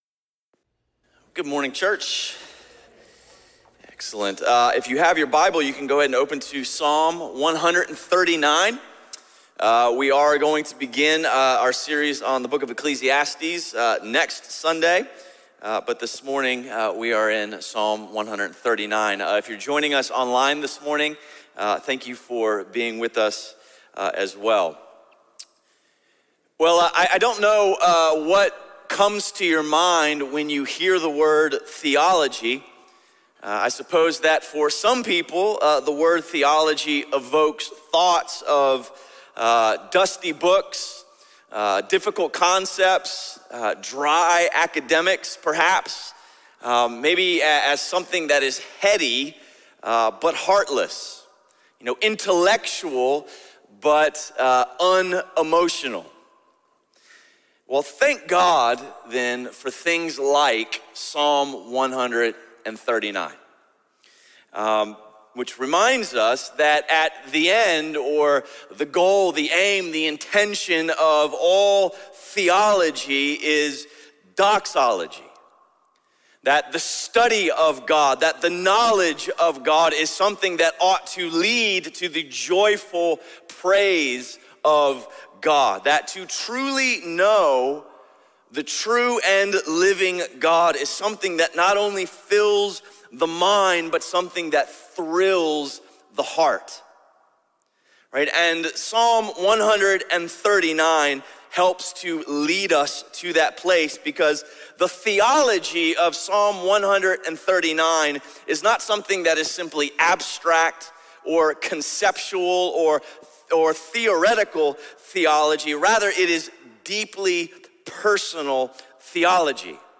A message from the series "The Lord\'s Prayer."